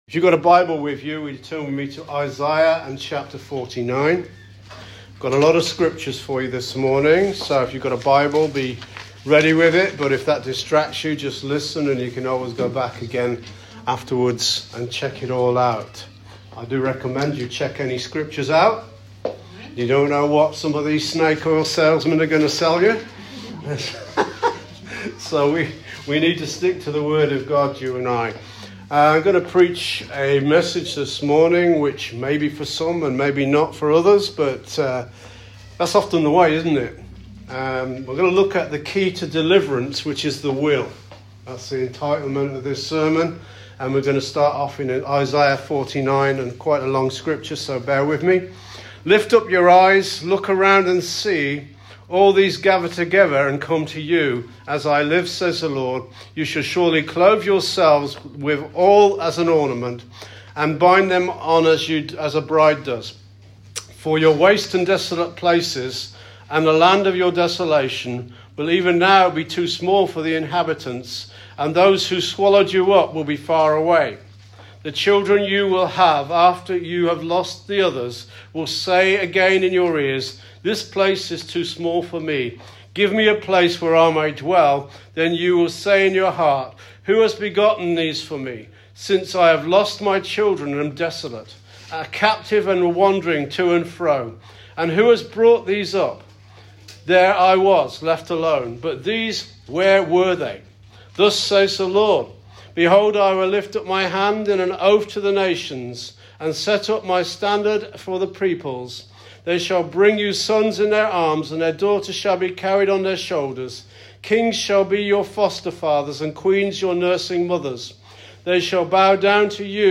Calvary Chapel Warrington Sermons / SERMON "THE KEY TO DELIVERANCE